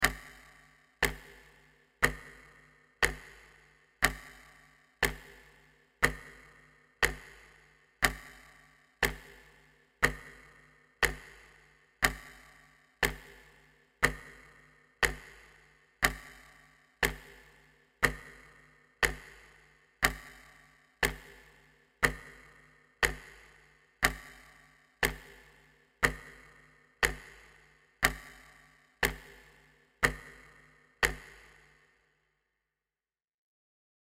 Звуки тиканья
Звук тревожного ожидания, мерное тиканье времени